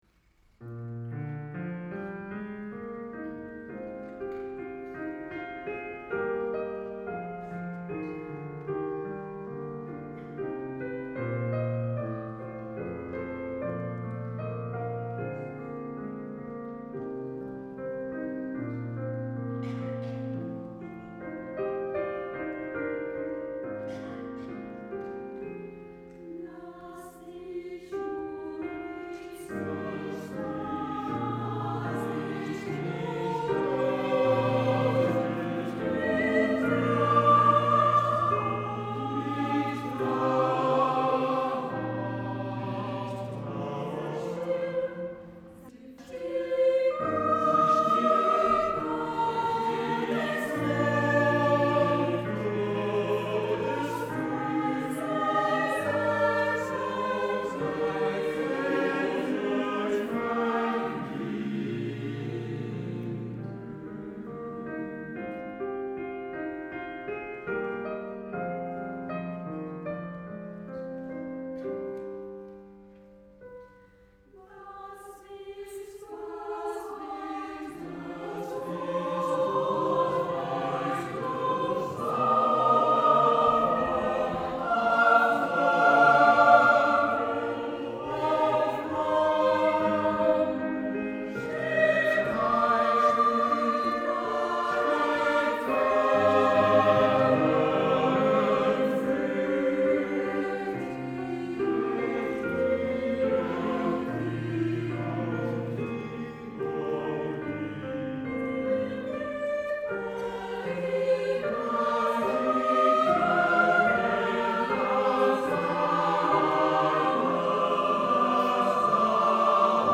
Concert public Poirel 2018 – Ensemble Vocal
piano